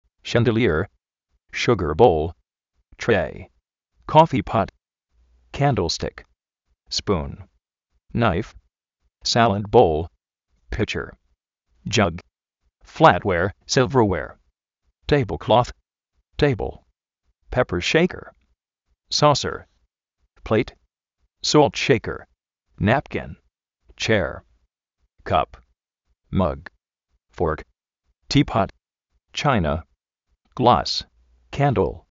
shandelié:
shúgar bóul
flát-uér, sílver-uér
péper shéiker